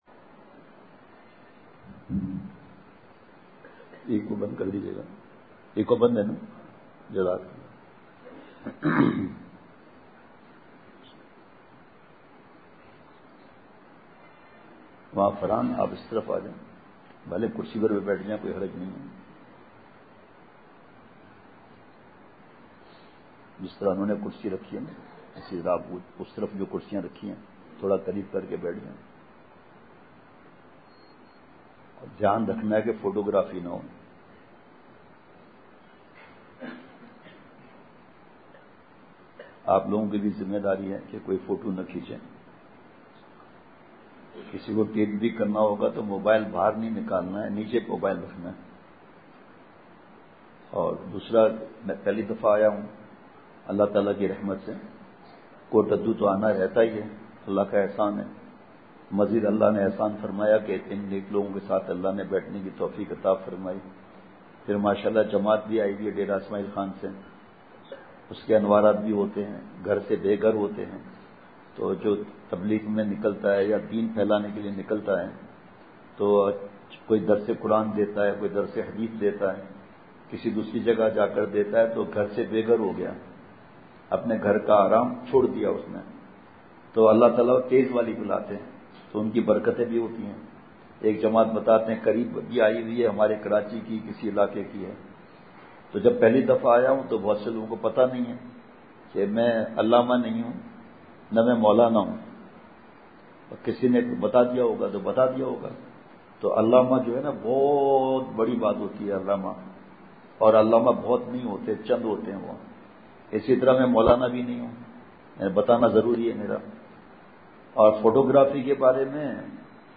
بمقام:جامع مسجد المجاہد سنانواں